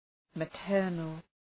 {mə’tɜ:rnəl}
maternal.mp3